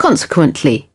6. consequently (adj) /ˈkɒnsɪkwəntli/ : vì vậy